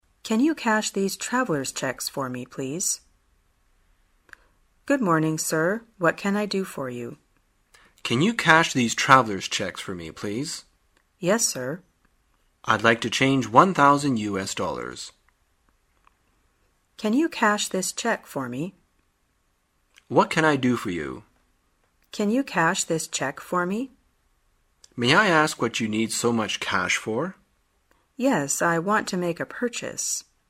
旅游口语情景对话 第233天:如何兑现支票